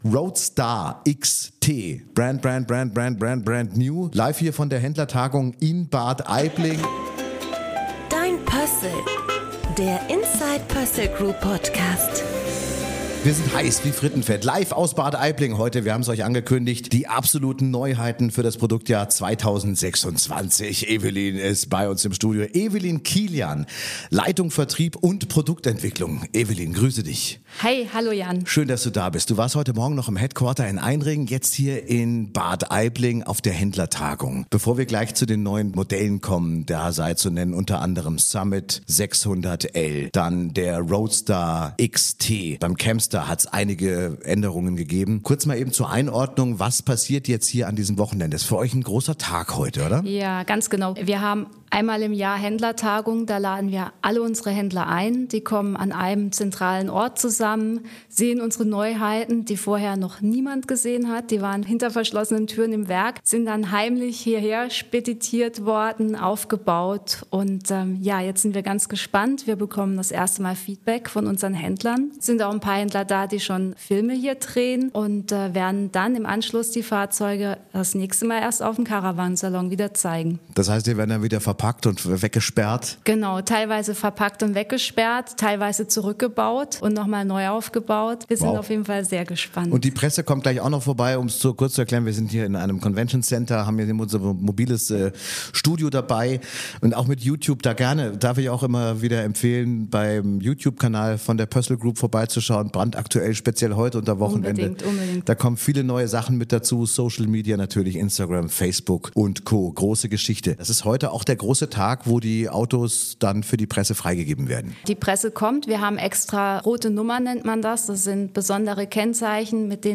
Premierenzeit: Roadstar XT, Summit 600L, Campstar. WIr melden uns heute direkt von der Pössl Group Händlertagung 2025 in Bad Aibling, auf der alle Produktneuheiten für 2026 vorgestellt werden.